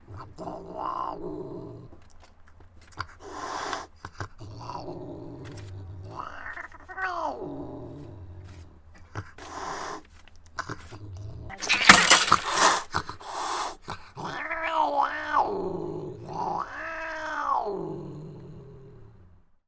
cat-sound